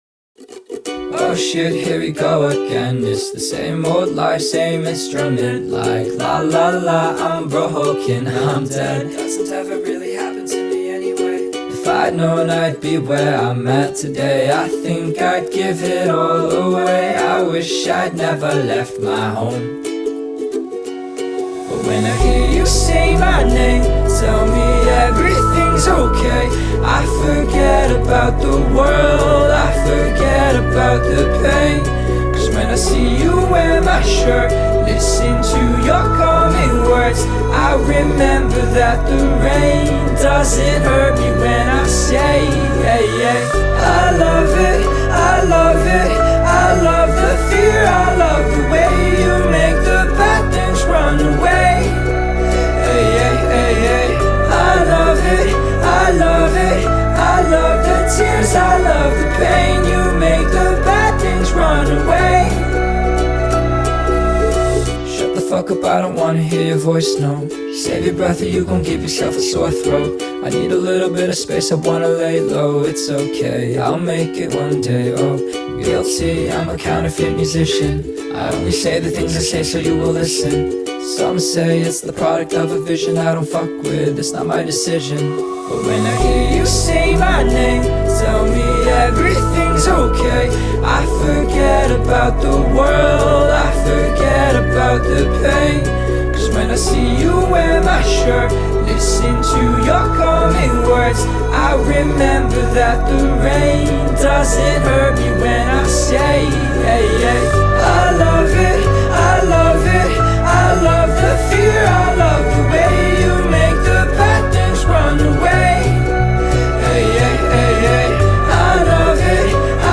• Жанр: Alternative